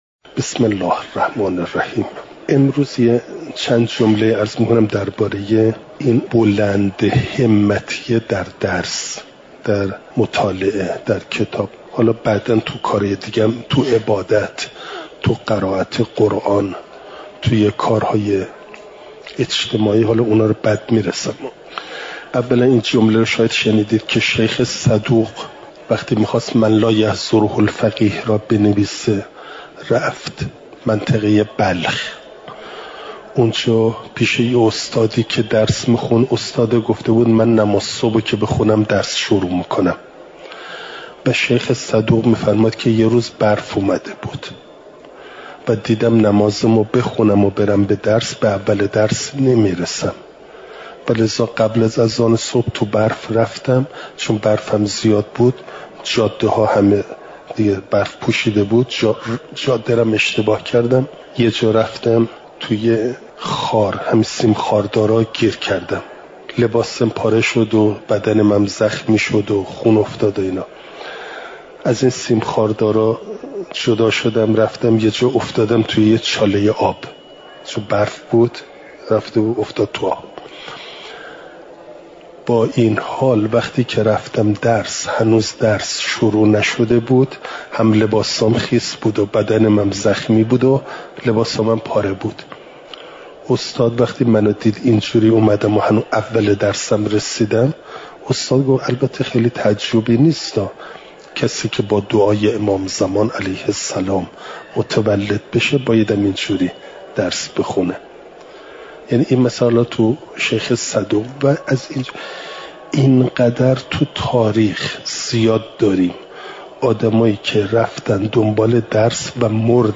چهارشنبه ۳۰ آبانماه ۱۴۰۳، حرم مطهر حضرت معصومه سلام ﷲ علیها